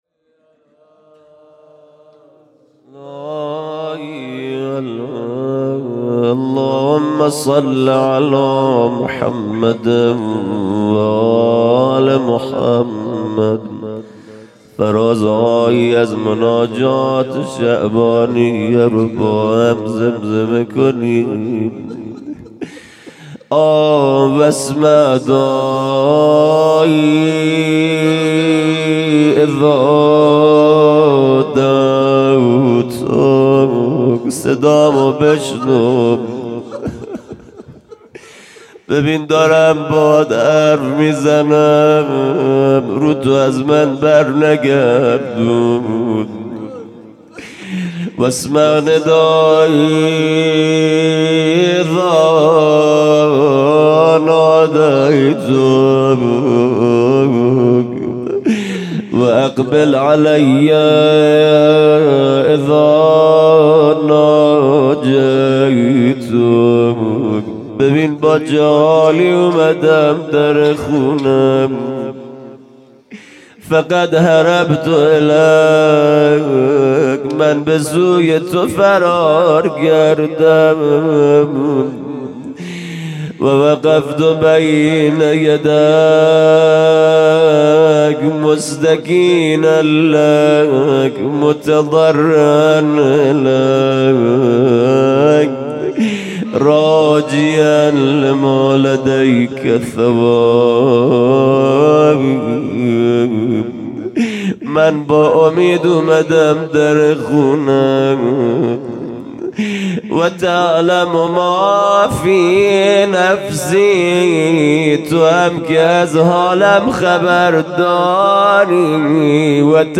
جلسۀ هفتگی (مراسم استقبال از ماه رمضان)